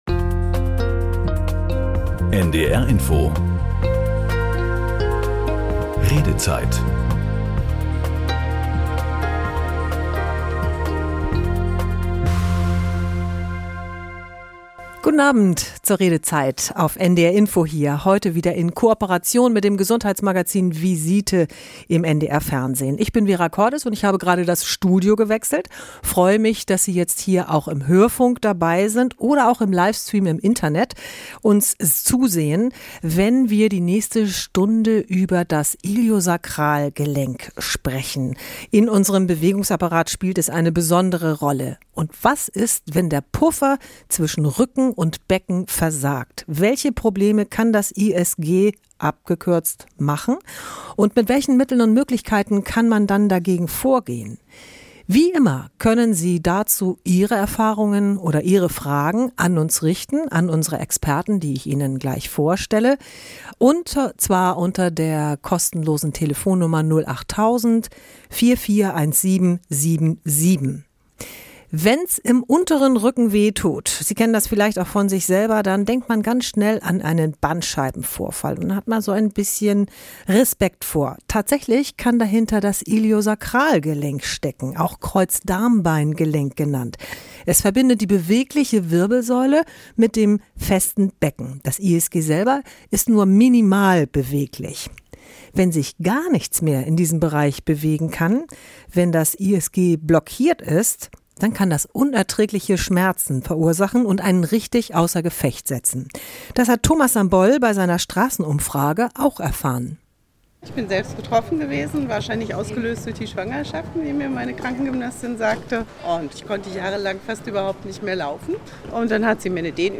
Über die Sendung Redezeit: An drei Abenden in der Woche können Anrufer mit unseren Experten diskutieren, nachdenken und streiten – oder sie um Rat fragen. Prominente und sachkundige Studiogäste liefern Argumente und klären über Zusammenhänge und Hintergründe auf.